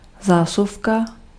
Ääntäminen
Ääntäminen France: IPA: /ti.ʁwaʁ/ Haettu sana löytyi näillä lähdekielillä: ranska Käännös Ääninäyte Substantiivit 1. šuplík {m} 2. šuple Muut/tuntemattomat 3. zásuvka {f} Suku: m .